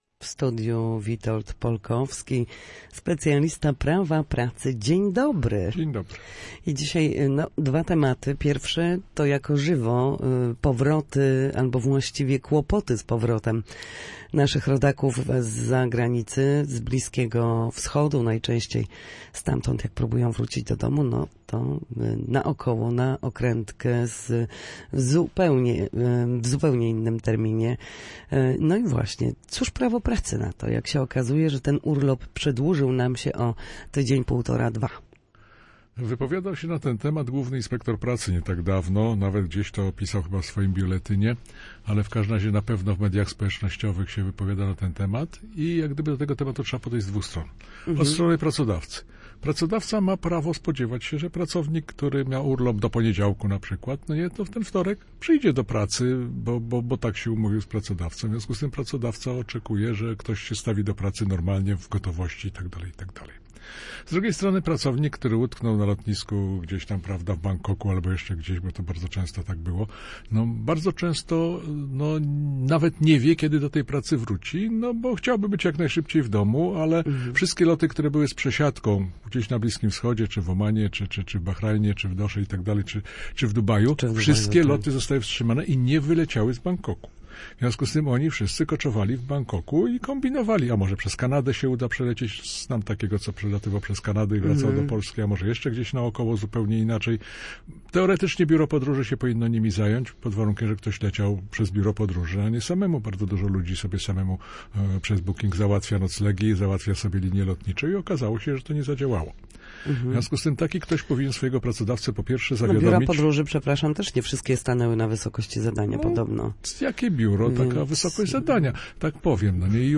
W każdy wtorek, po godzinie 13:00, na antenie Studia Słupsk przybliżamy zagadnienia dotyczące prawa pracy.